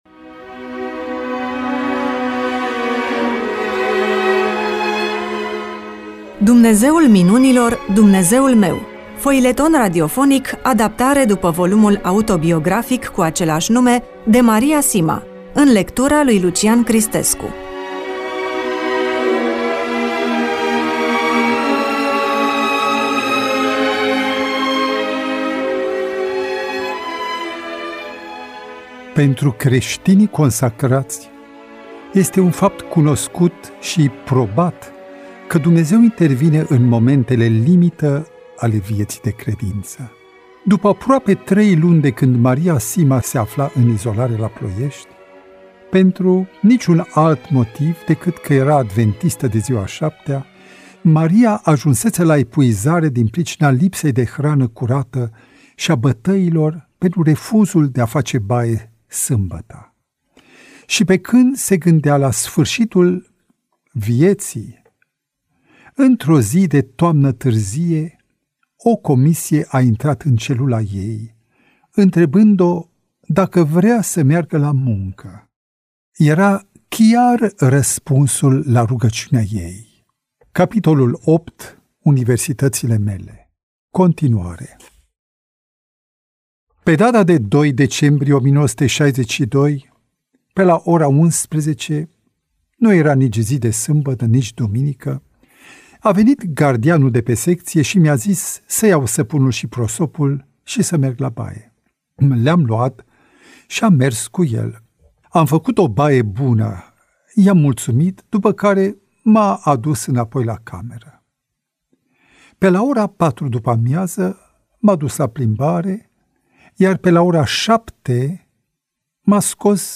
EMISIUNEA: Roman foileton DATA INREGISTRARII: 13.02.2026 VIZUALIZARI: 36